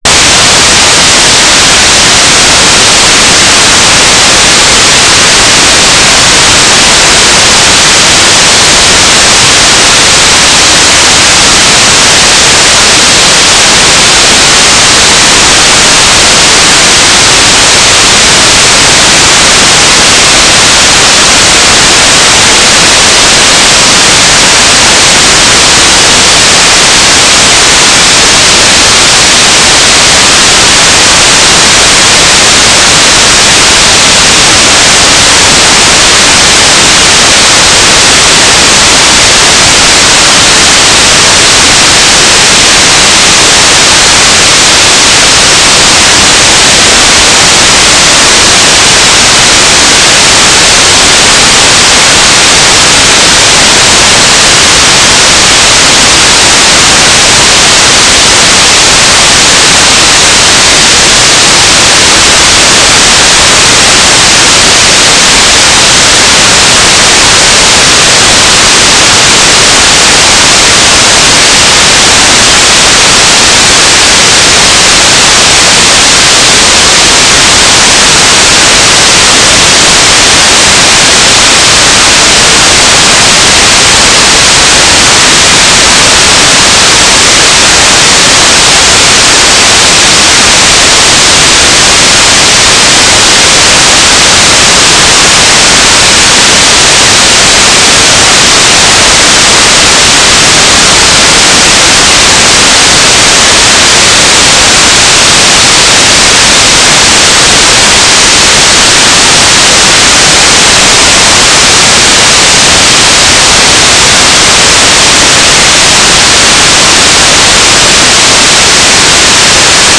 "waterfall_status": "without-signal",
"transmitter_description": "Mode U - GFSK9k6 - AX.25 Beacon and Telemetry (Geoscan framing)",